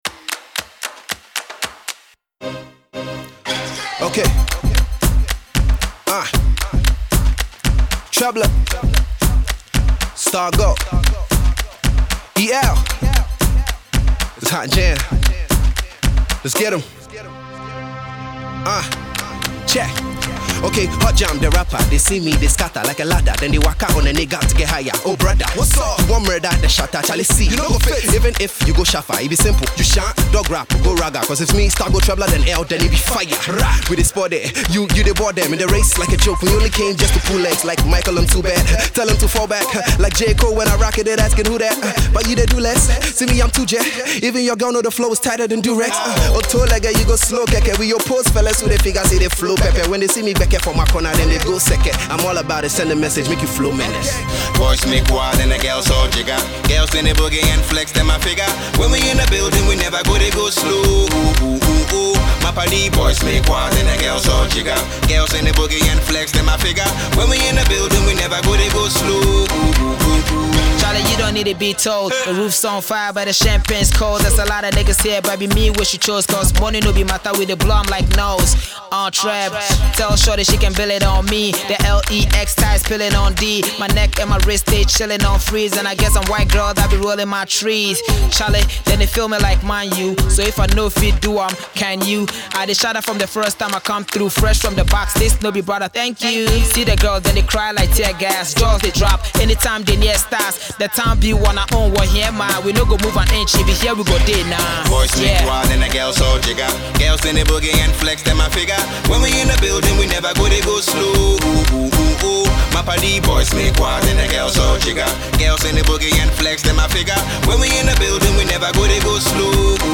and some hot-as-fire verses from the UK-based rapper